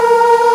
Index of /m8-backup/M8/Samples/Fairlight CMI/IIX/CHORAL
MMM.WAV